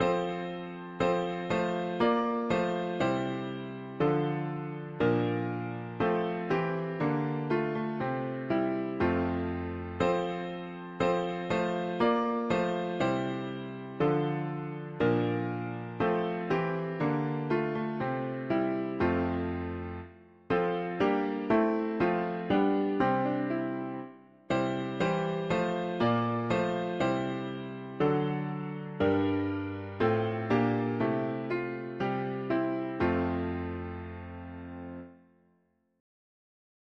Tags english christian 4part winter